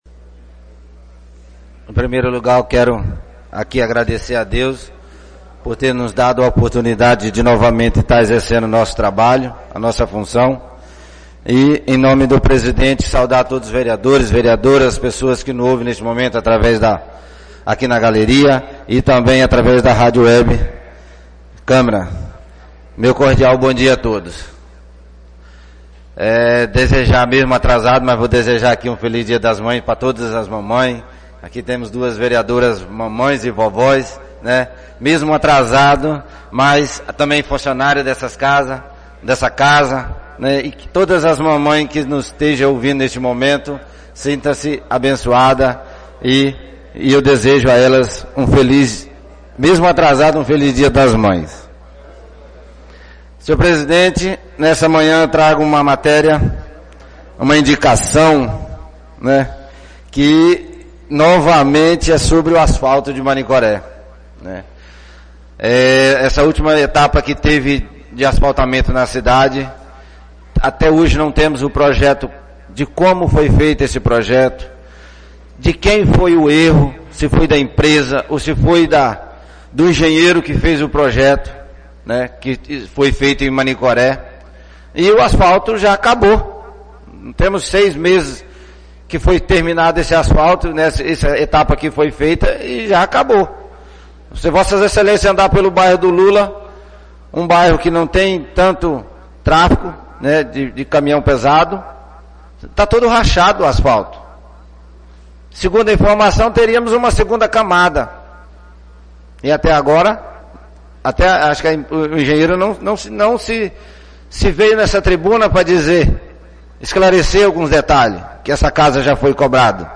Oradores do Expediente (26ª Sessão Ordinária da 3ª Sessão Legislativa da 31ª Legislatura)
1_fala_cloves.mp3